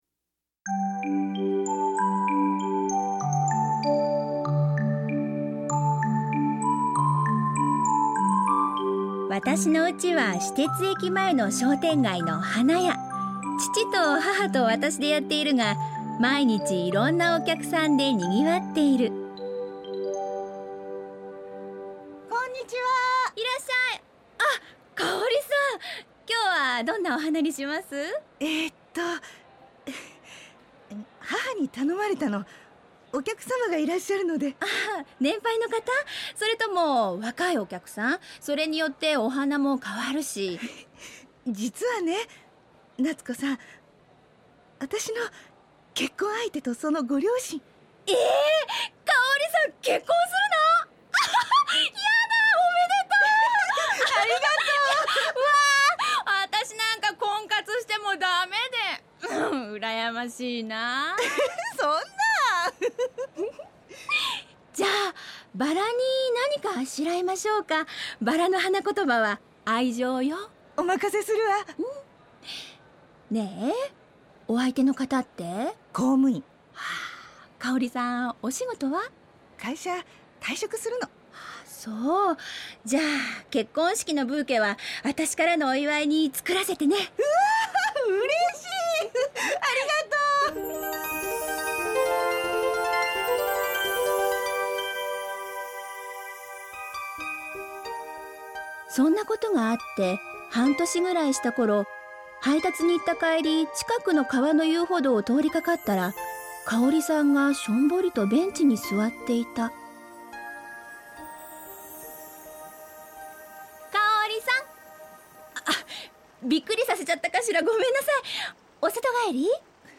ラジオドラマ「花ものがたり」第１回「バラ」